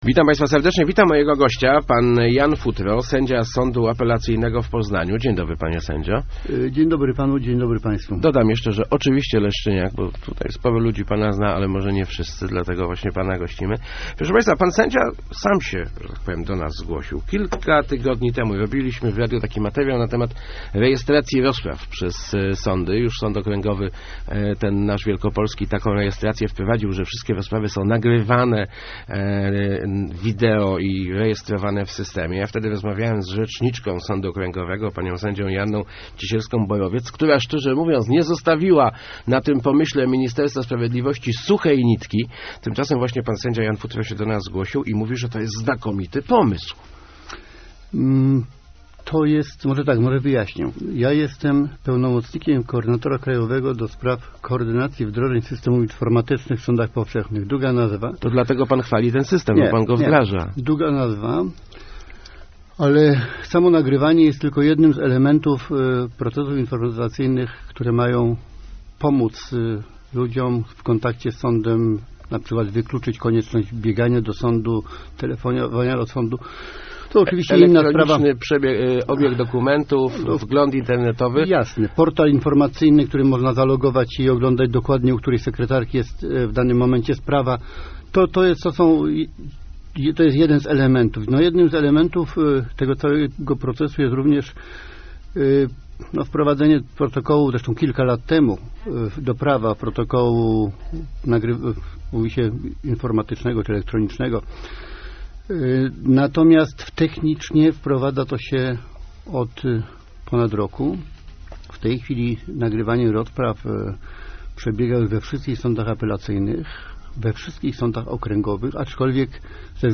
Nagrywanie rozpraw sądowych rzeczywiście skróci postępowania - zapewniał w Rozmowach Elki Jan Futro, sędzia Sądu Apelacyjnego w Poznaniu.